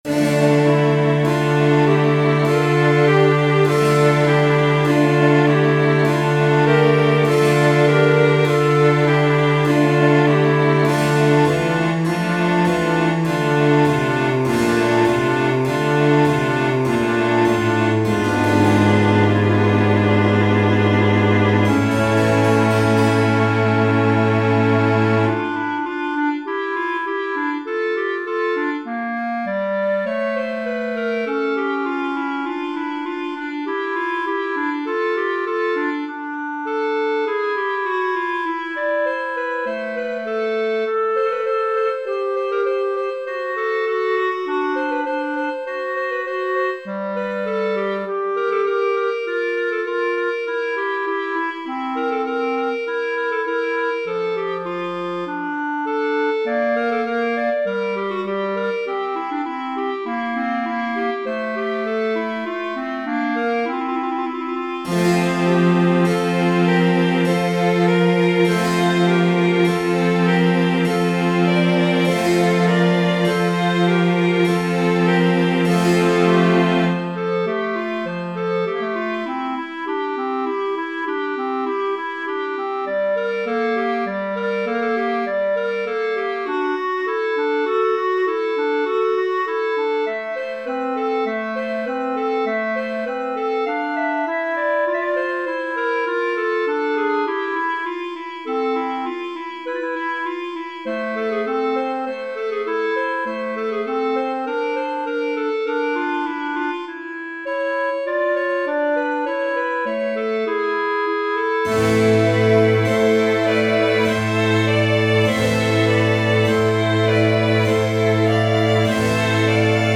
Free Sheet music for Mixed Ensemble
Chalumeau 1Chalumeau 2Violin 1Violin 2ViolaBass
Classical (View more Classical Mixed Ensemble Music)